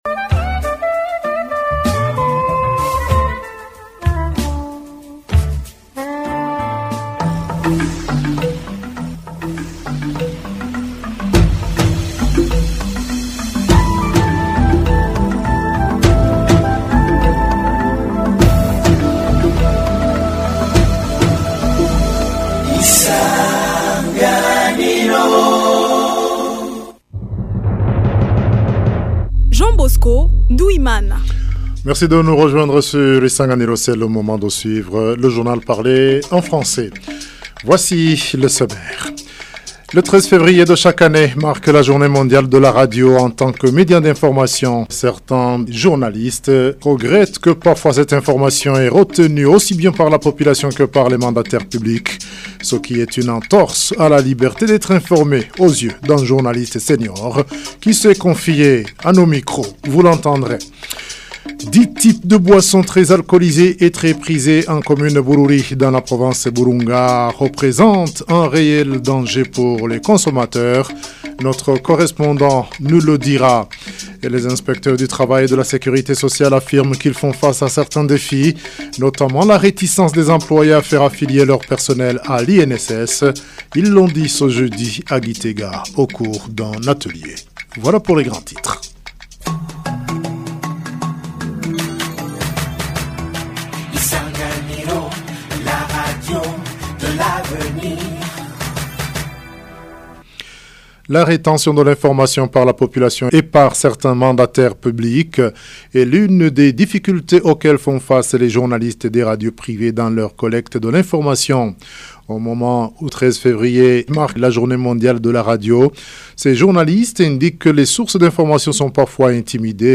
Journal du 13 février 2026